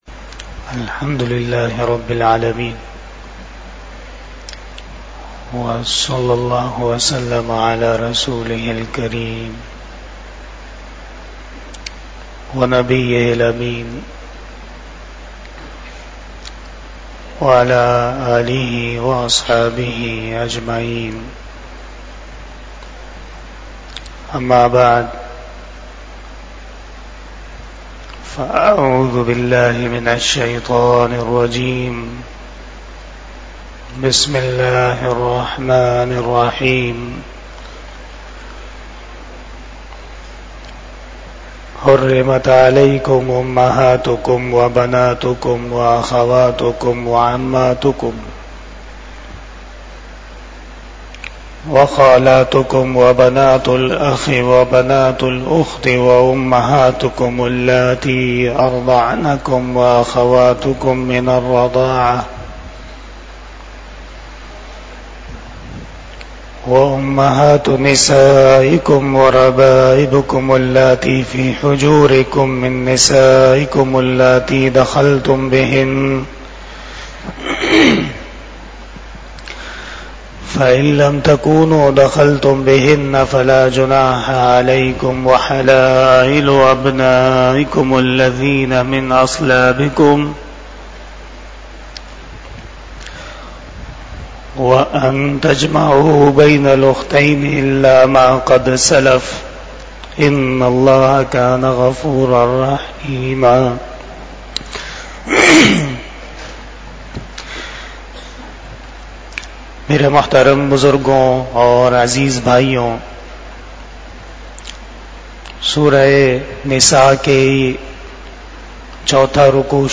19 Shab E Jummah Bayan 13 June 2024 (08 Zil Hajjah 1445 HJ)